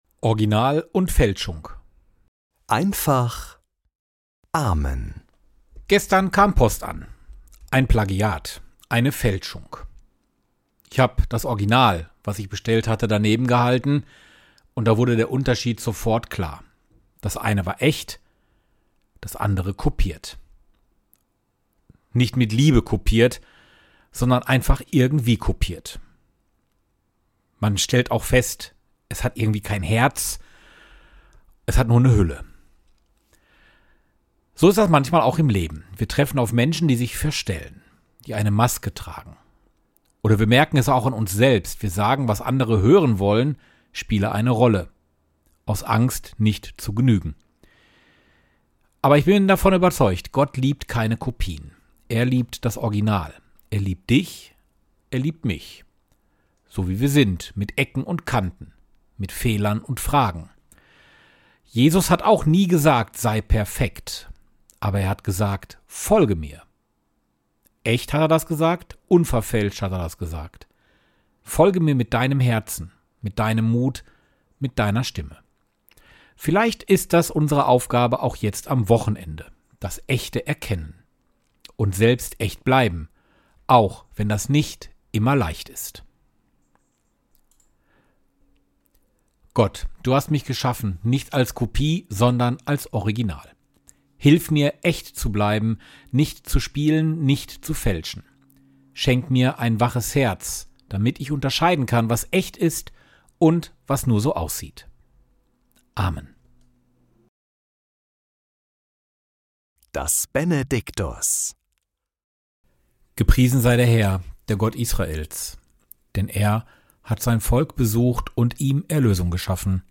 Morgenimpuls in Einfacher Sprache
Der Podcast mit Gebeten, Impulsen und Gedanken - in einfacher Sprache.